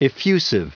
Prononciation du mot effusive en anglais (fichier audio)
Prononciation du mot : effusive